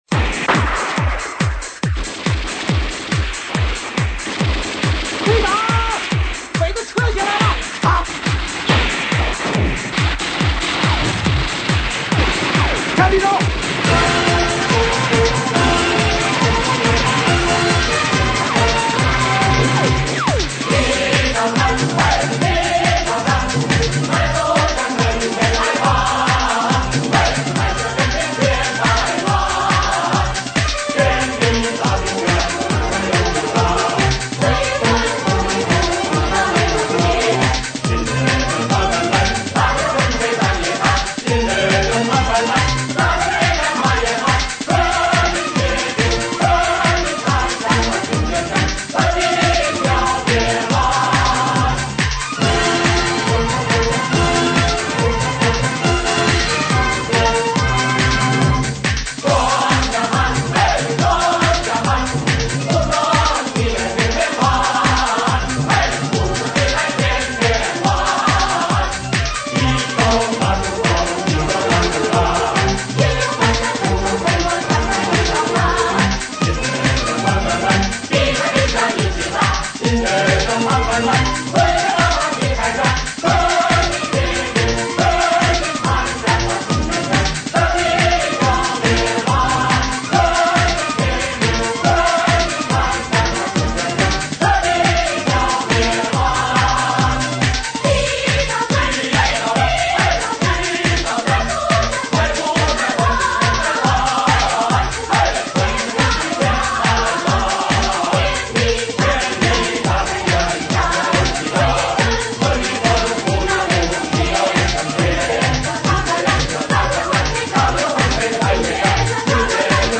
历史录音与现代迪斯科音乐合成曲